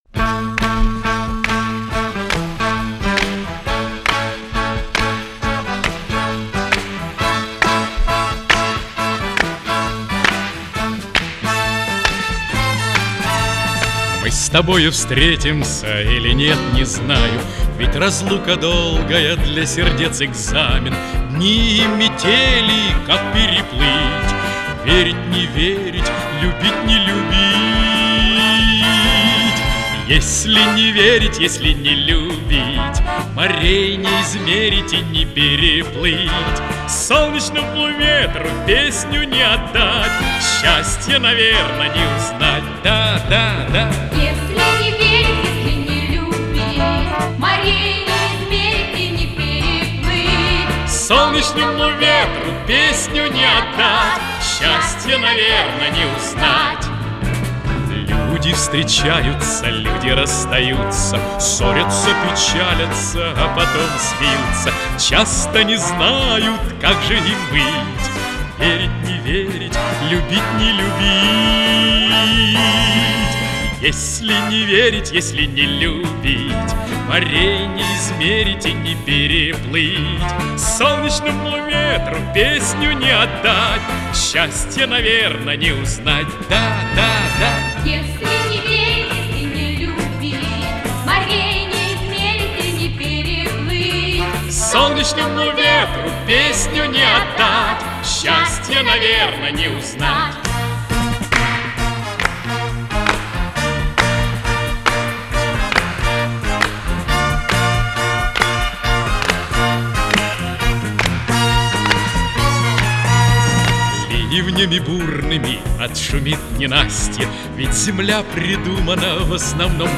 Солирует певица с очень звонким голосом.